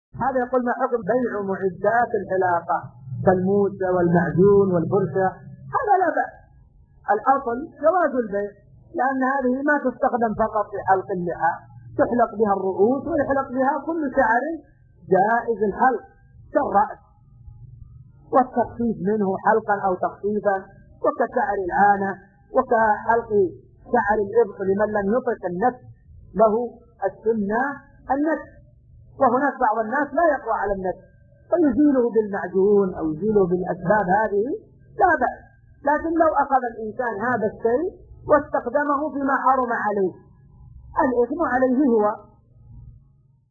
السؤال مقتطف من شرح كتاب الصيام من زاد المستقنع .
ملف الفتوي الصوتي عدد الملفات المرفوعه : 1